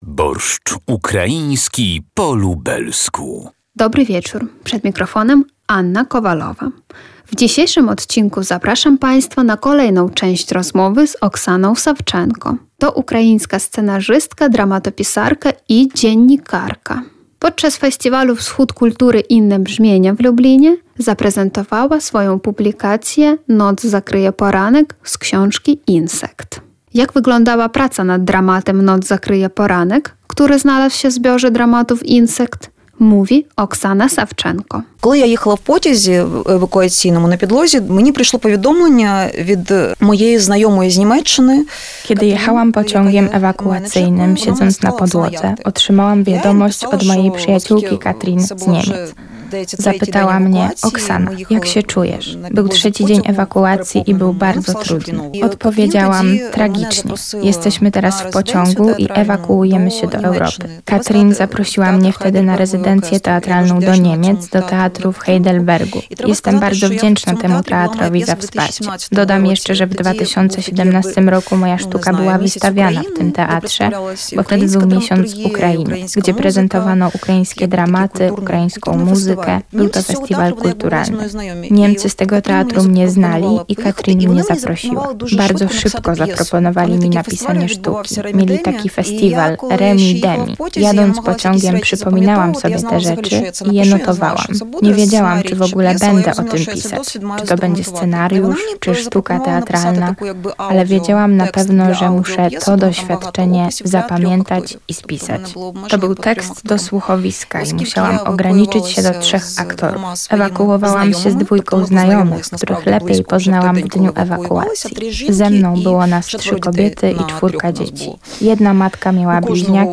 To ukraińska scenarzystka, dramatopisarka i dziennikarka.